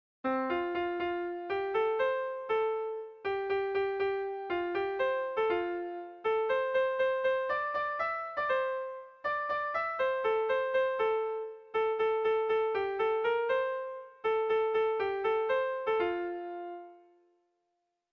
ABDE